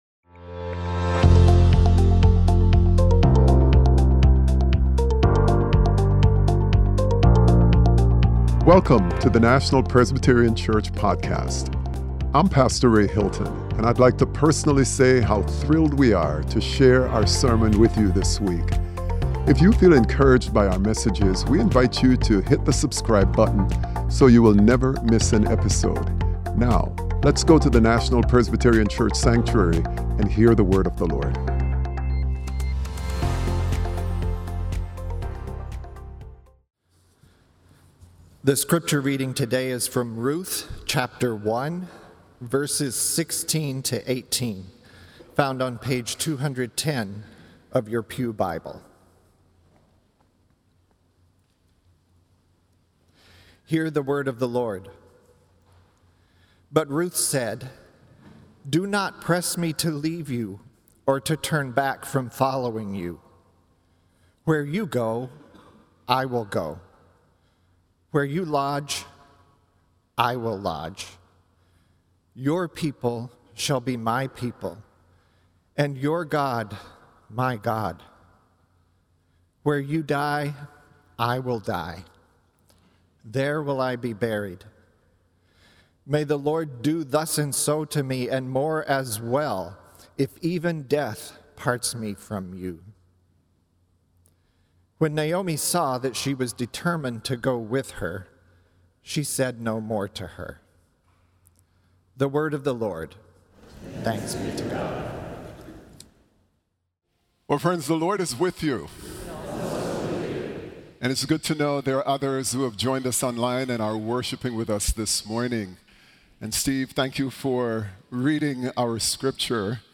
Sermon: Flourishing Together - Love in the Hard Places - National Presbyterian Church